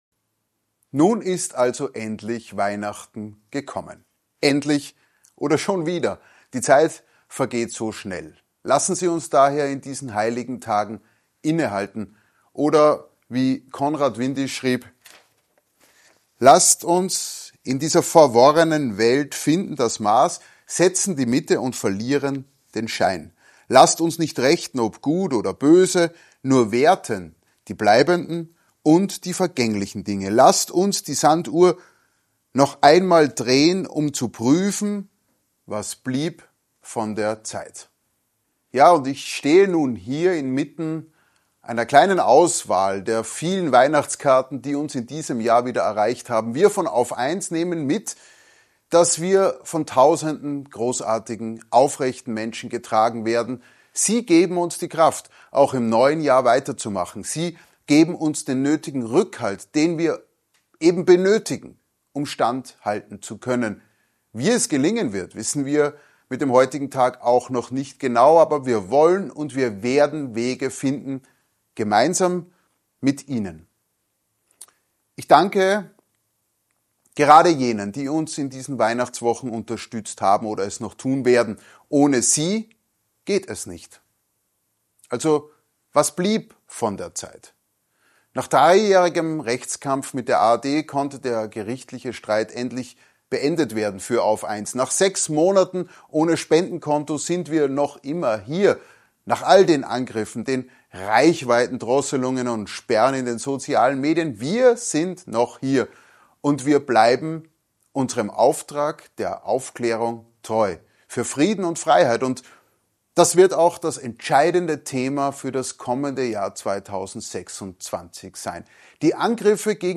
Frohe Weihnachten aus dem AUF1-Studio